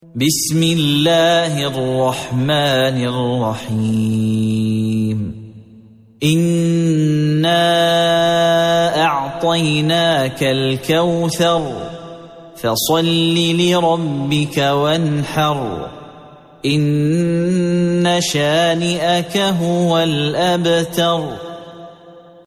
سورة الكوثر مكية عدد الآيات:3 مكتوبة بخط عثماني كبير واضح من المصحف الشريف مع التفسير والتلاوة بصوت مشاهير القراء من موقع القرآن الكريم إسلام أون لاين